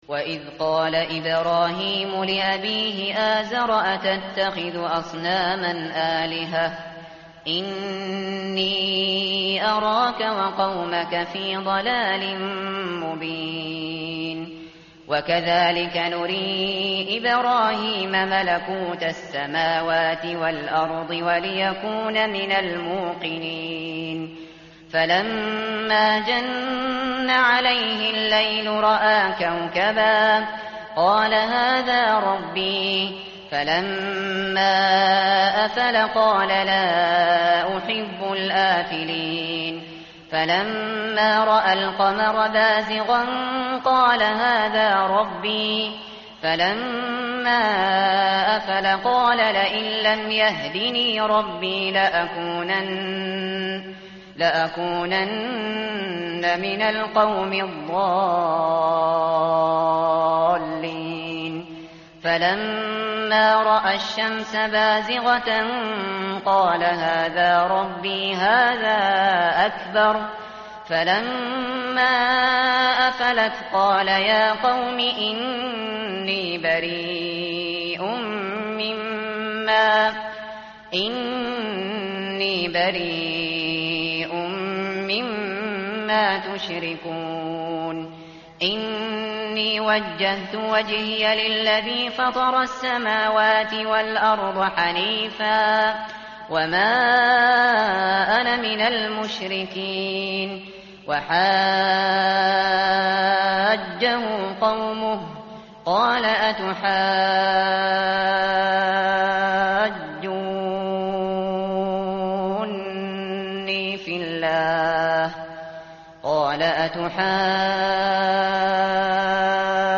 متن قرآن همراه باتلاوت قرآن و ترجمه
tartil_shateri_page_137.mp3